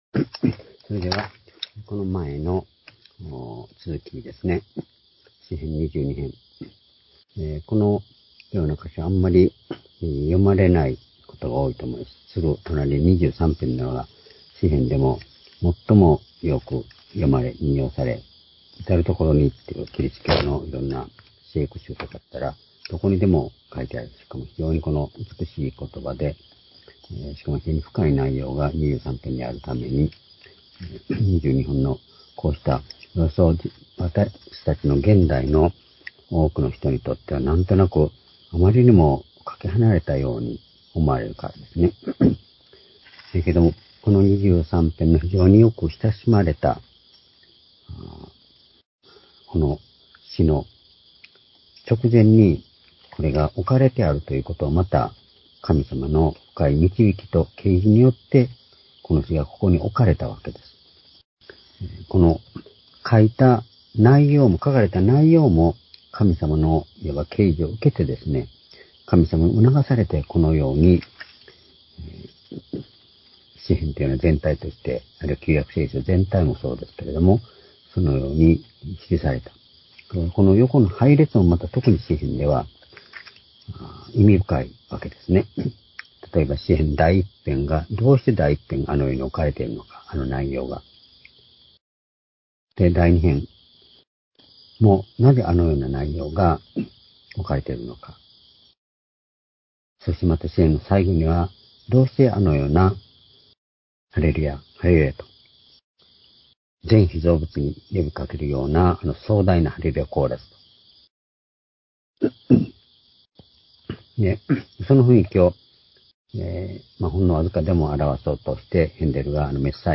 「わが力の神」詩編２２編７節－２２節-2021年10月5日（夕拝）